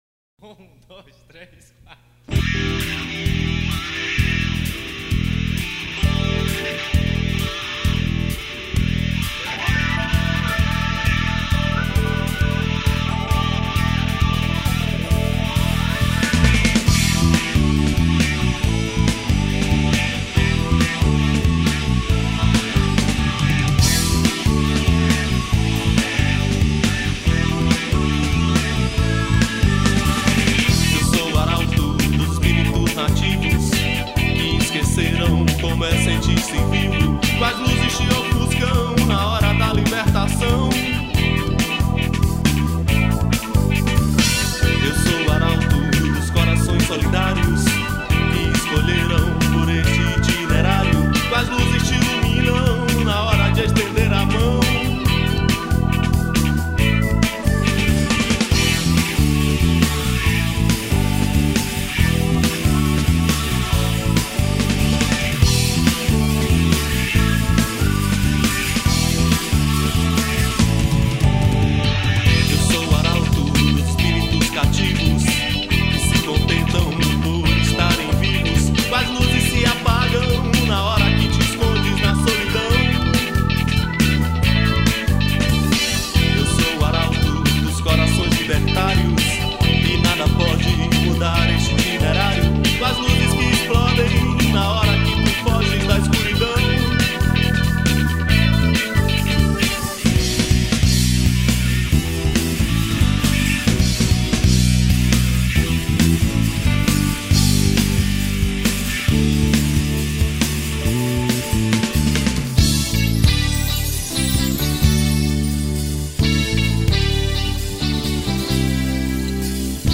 03:25:00   Rock Nacional
Baixo Elétrico 6
Teclados
Percussão
Guitarra, Cavaquinho, Violao 7
Voz
Bateria